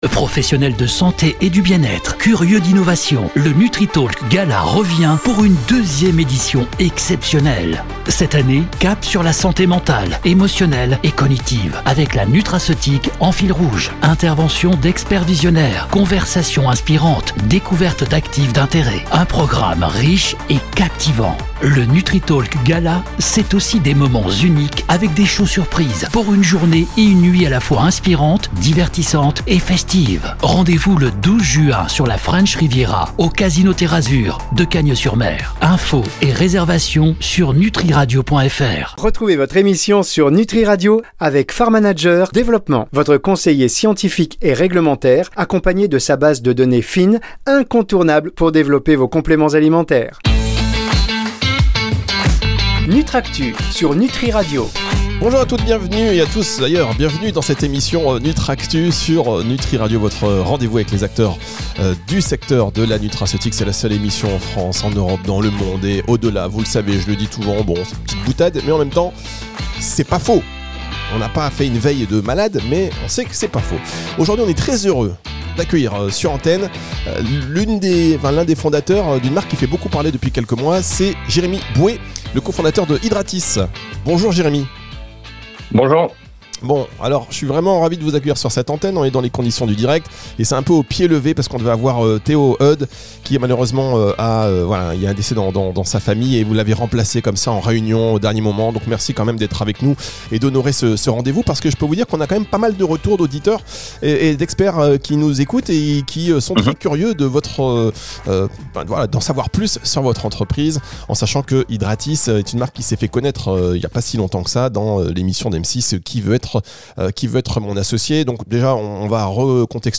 Un échange sans filtre sur les opportunités et les dérives de cette nouvelle tendance.